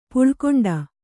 ♪ puḷkoṇḍa